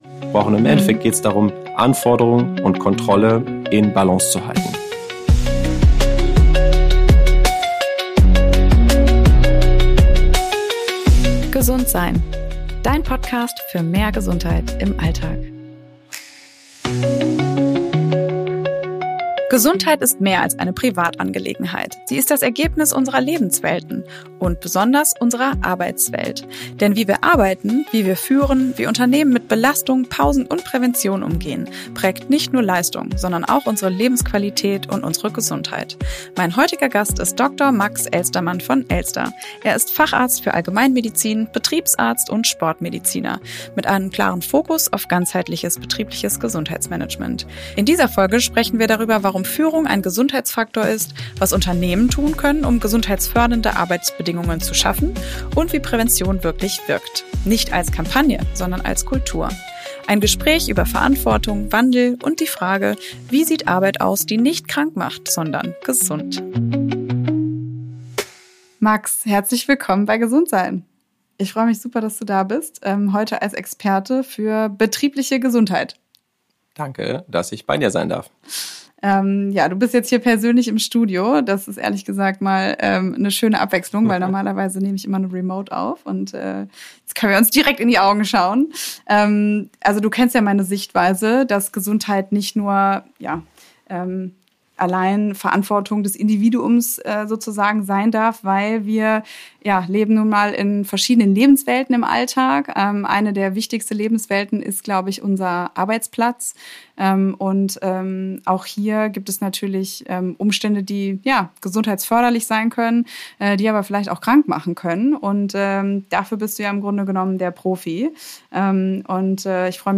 Ein Gespräch über die Zukunft der Arbeit – und darüber, warum Unternehmen heute handeln müssen.